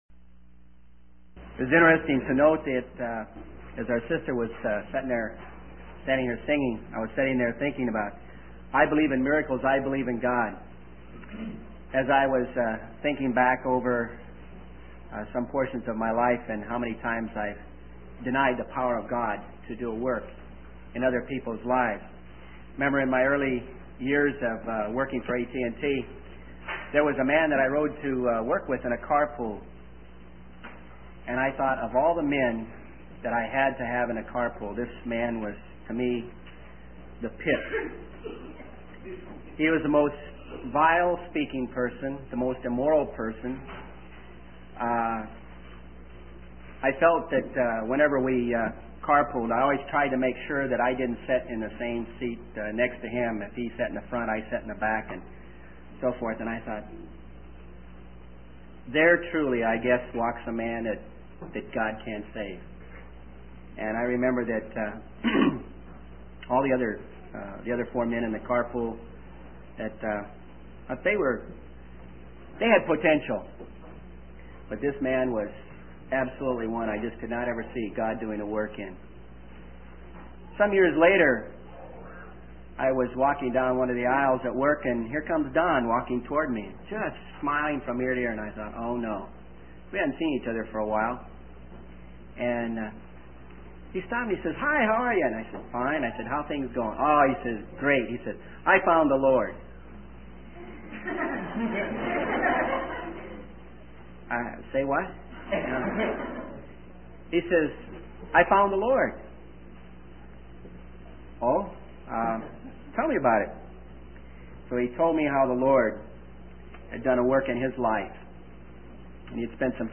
In this sermon, the speaker shares a personal story about a near-drowning experience to illustrate the consequences of disobedience. He emphasizes the importance of doing the right thing, even when it may be inconvenient or difficult.